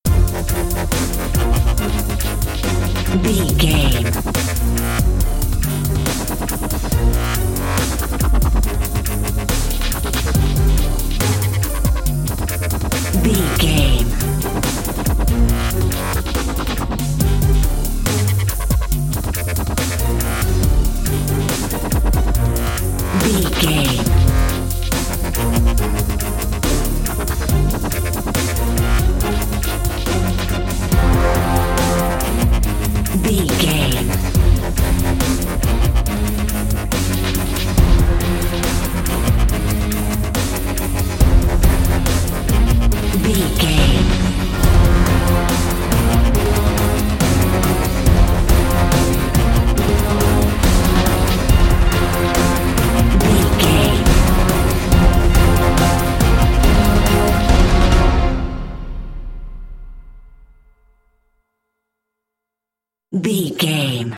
Epic / Action
Fast paced
In-crescendo
Dorian
synthesiser
drum machine
electric guitar
orchestral
orchestral hybrid
dubstep
aggressive
energetic
intense
strings
bass
synth effects
wobbles
driving drum beat